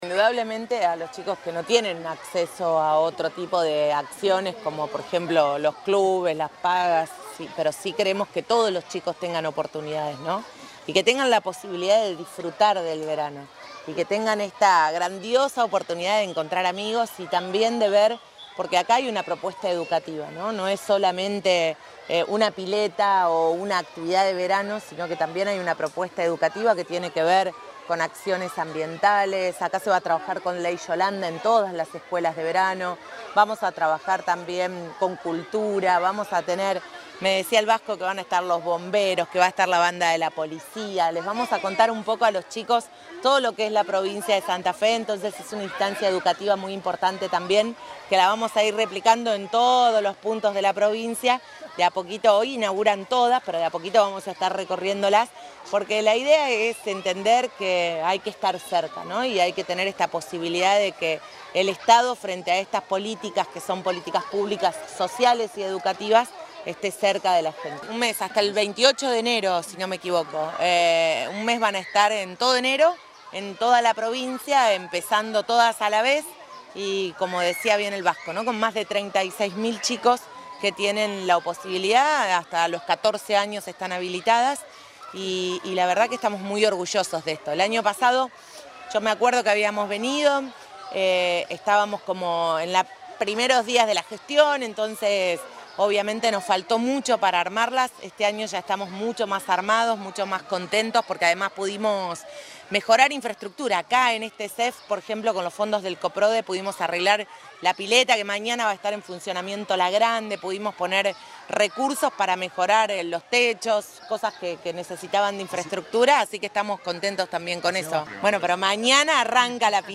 En ese marco, la vicegobernadora Gisela Scaglia junto a la secretaria de Deportes, Flavia Padín y el director provincial de Educación Física, Adrián Alurralde, visitaron el CEF 29 de la ciudad de Santa Fe donde dejaron oficialmente inaugurada la edición 2025 de esta iniciativa que tiene como objetivo constituirse en un espacio pedagógico que dé continuidad al proceso de alfabetización.
Declaraciones de Scaglia, Padín y Alurralde